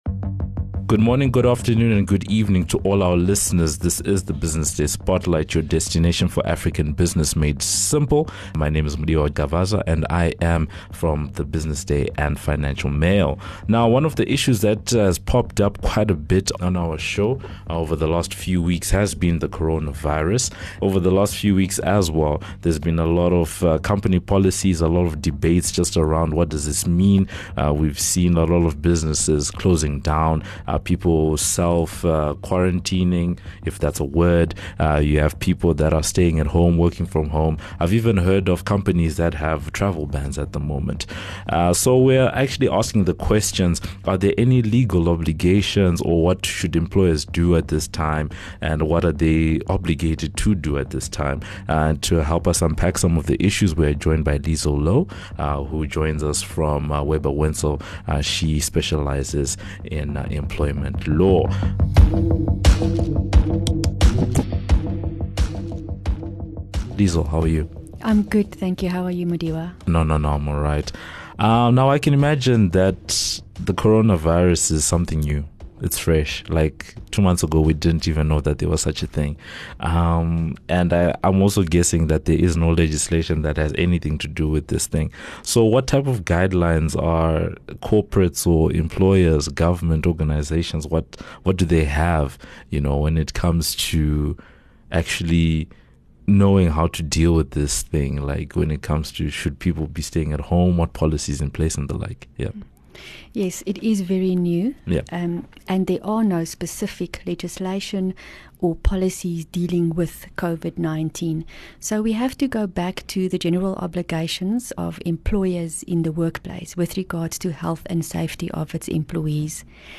ENGLISH SOUTH AFRICA